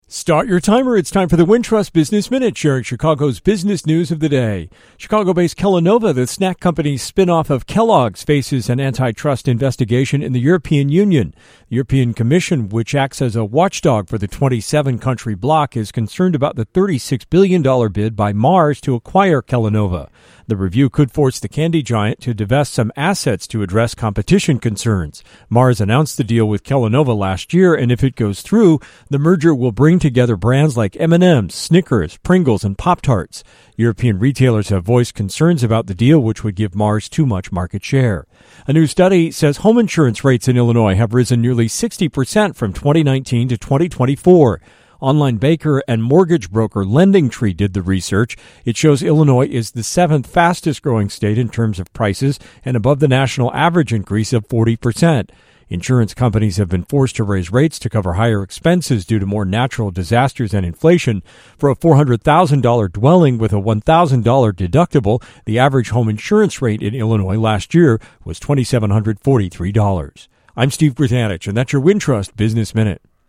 business news of the day